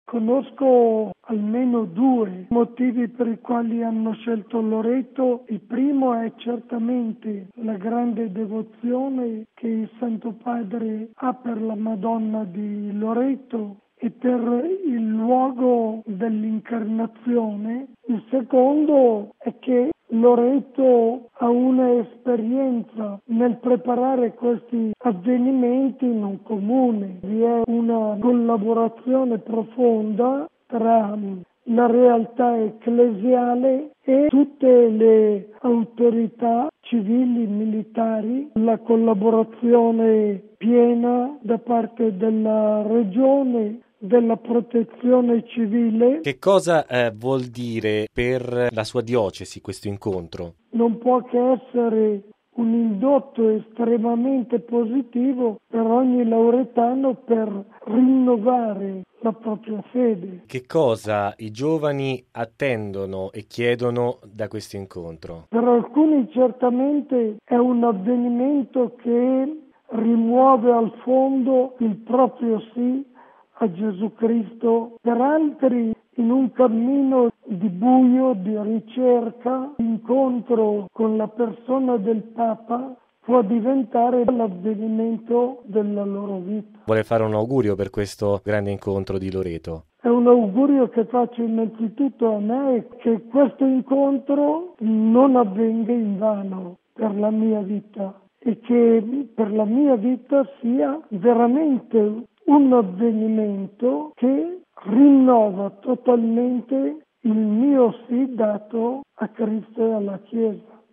Impegnati in prima linea, ovviamente, i responsabili della pastorale giovanile della Conferenza episcopale italiana (CEI), che hanno scelto Loreto per due ragioni. A sottolinearlo è mons. Gianni Danzi, arcivescovo prelato di Loreto, intervistato